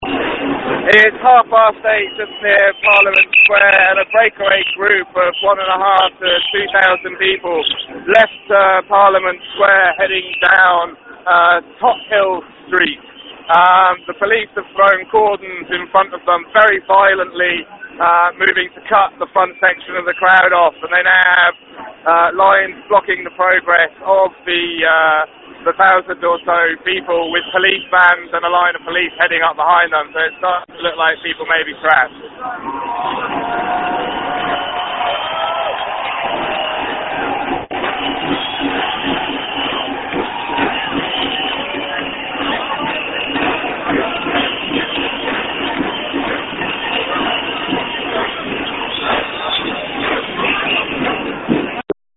Audio report from protest in parliament square 20:30